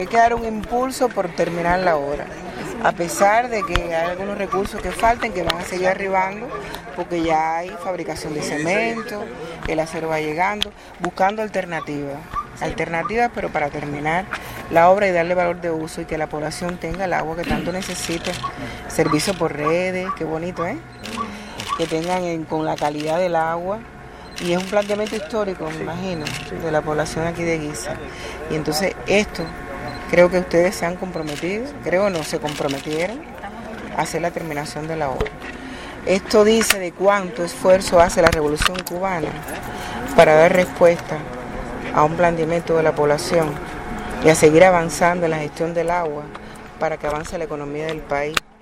Chapman Waugh chequeó en horas de la tarde de este jueves, 5 de septiembre, la obra planta potabilizadora del sistema de abasto de agua que se construye en dicha localidad granmense, la cual se encuentra a poco más del 80 por ciento de ejecución.
Palabras-de-Inés-María-Chapman.mp3